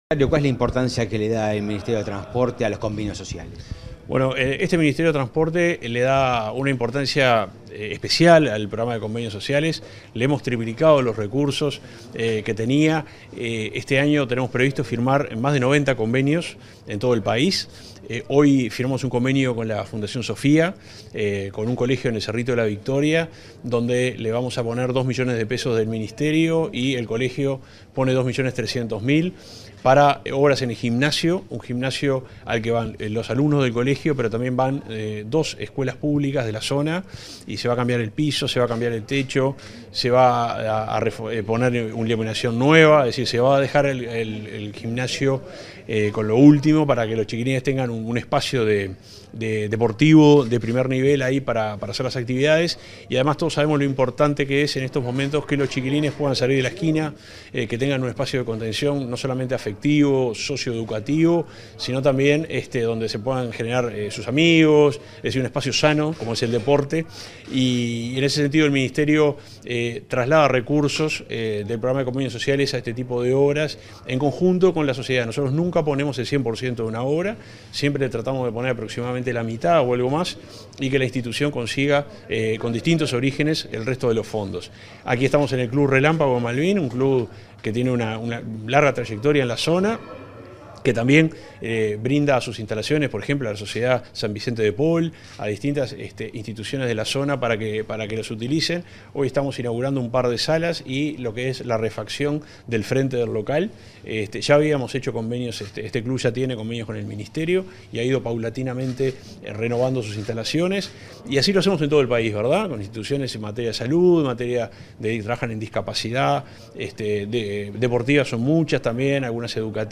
Entrevista al subsecretario de Transporte y Obras Públicas, Juan José Olaizola